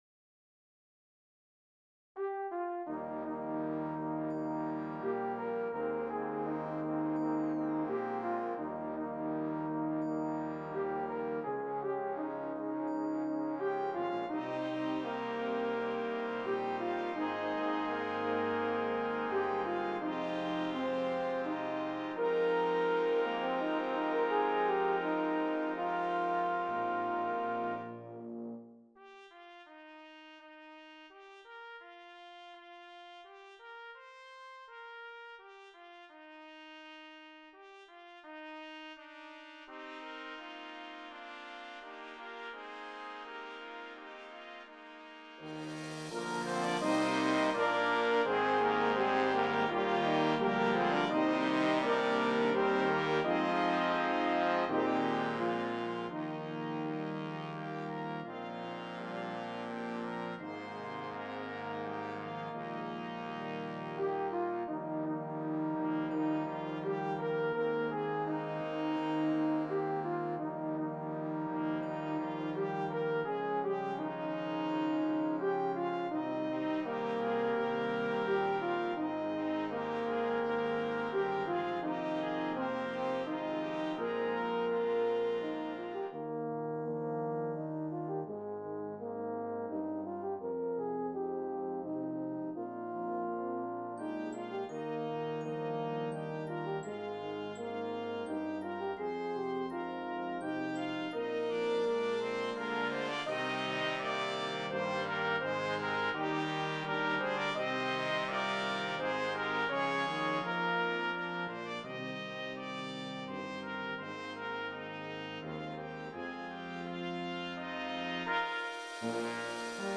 (Hymn Tune Arrangement)